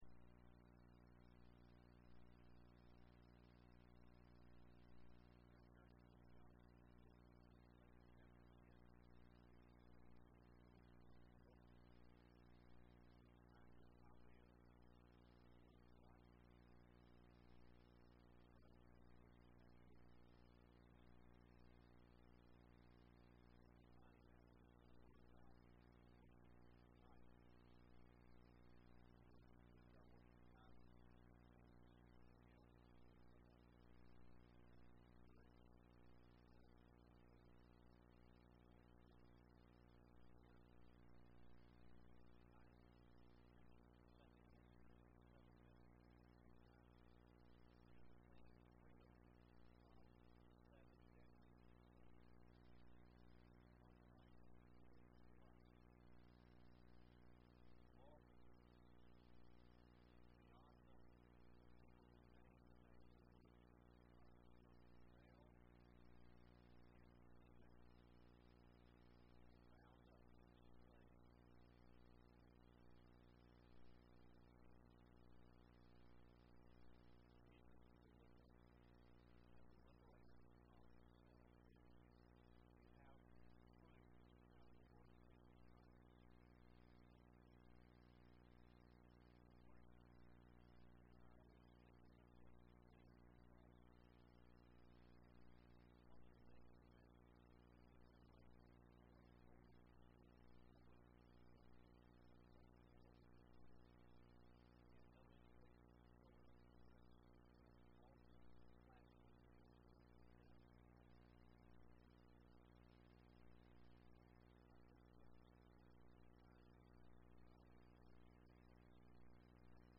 Lakeview Baptist Church - Auburn, Alabama
Missionary Joy Sermon